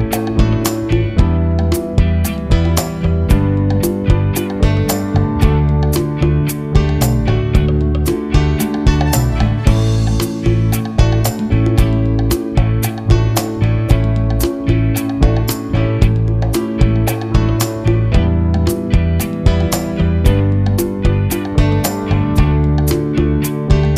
Key of D with No Saxophone Pop (1970s) 4:30 Buy £1.50